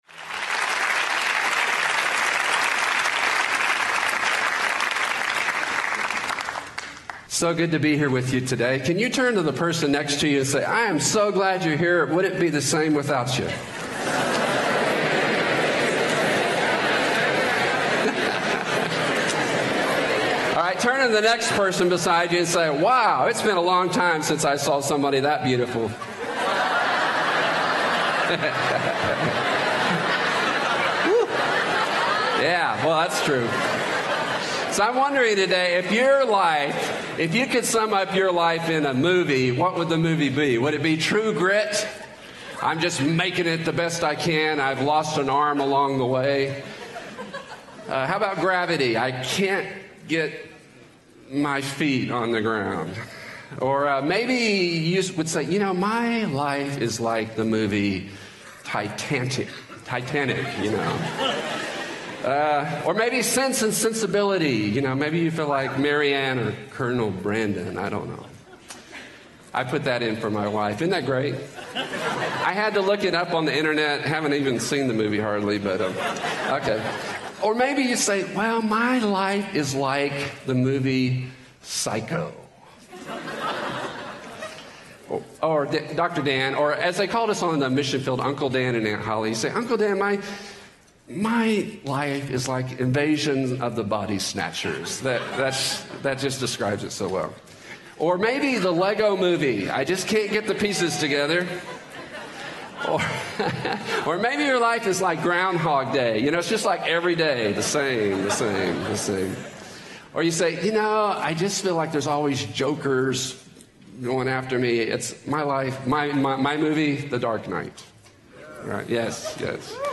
Chapels